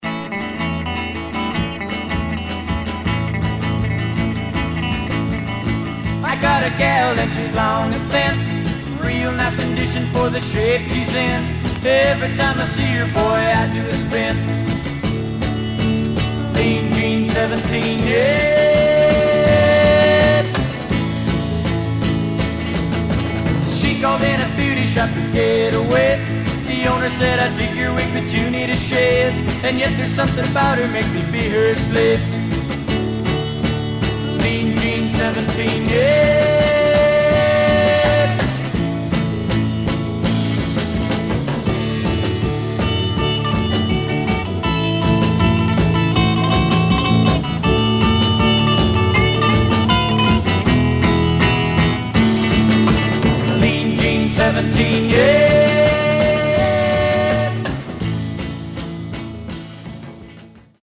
Some surface noise/wear
Mono
Garage, 60's Punk Condition